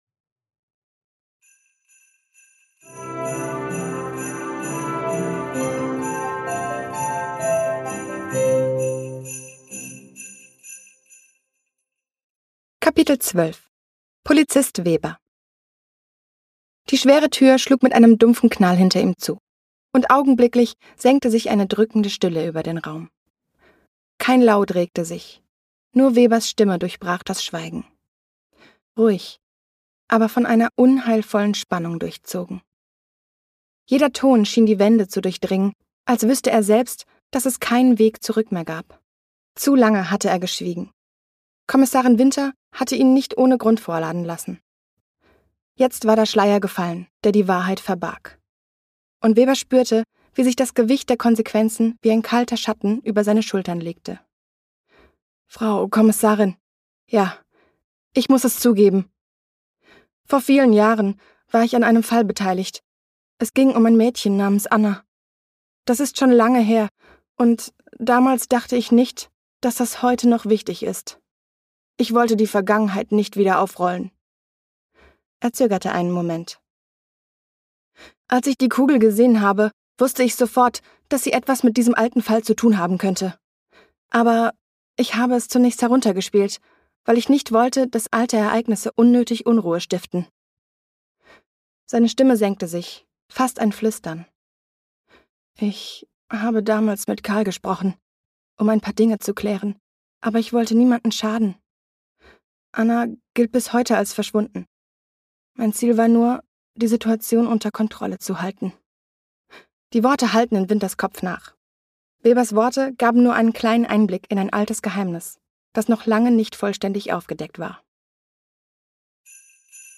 Lass dich von acht verzaubernden Stimmen in die